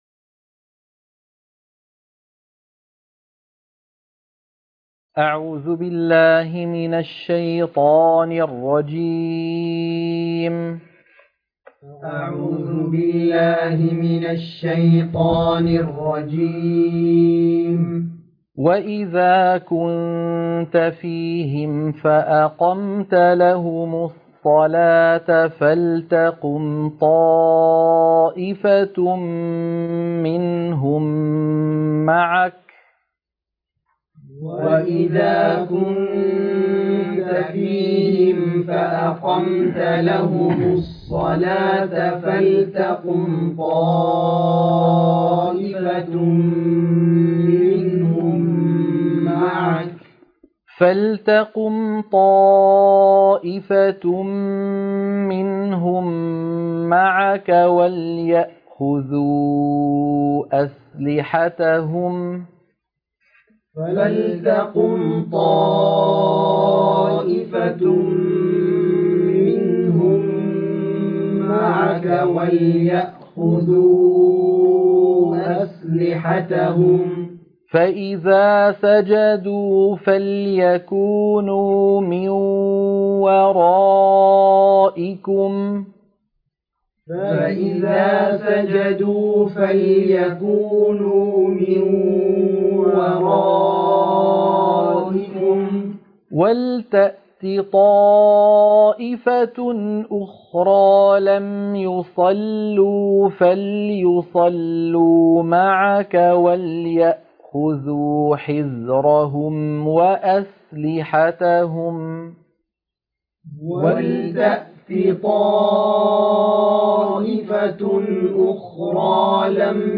عنوان المادة تلقين سورة النساء - الصفحة 95 التلاوة المنهجية